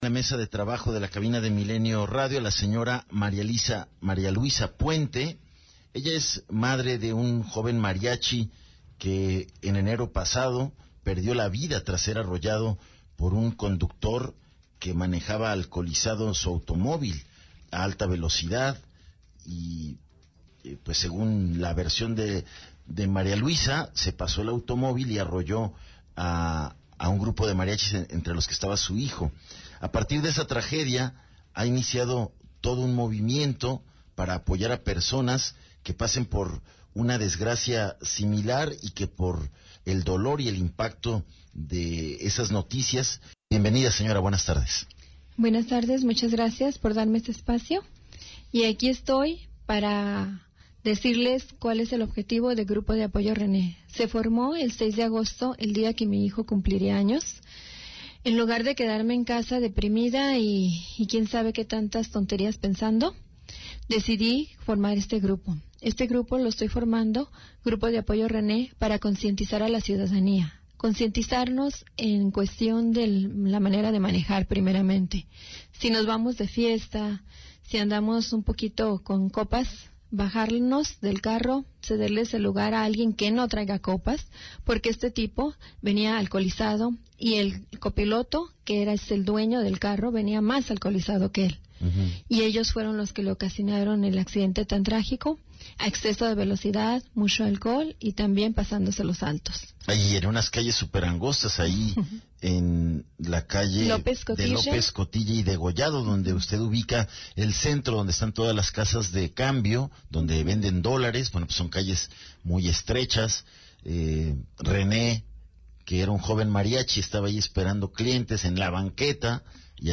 ENTREVISTA 060915